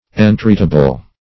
Entreatable \En*treat"a*ble\, a. That may be entreated.